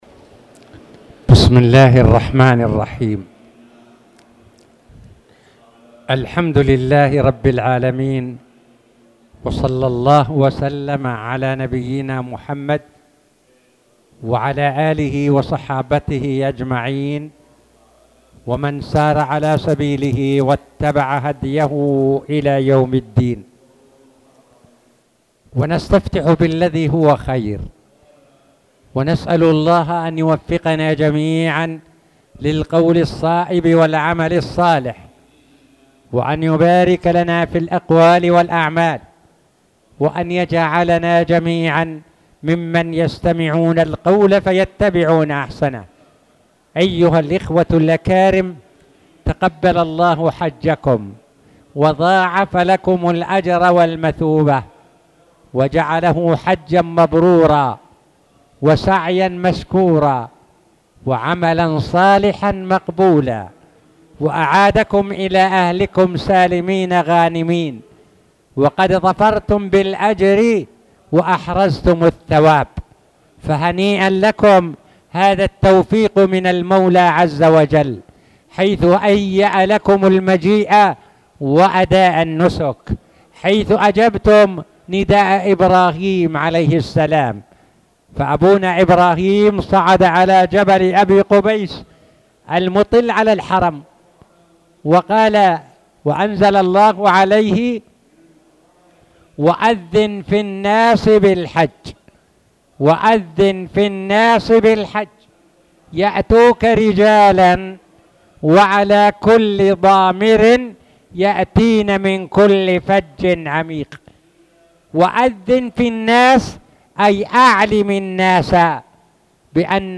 تاريخ النشر ١٨ ذو الحجة ١٤٣٧ هـ المكان: المسجد الحرام الشيخ